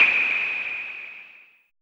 JUNO NOISE5.wav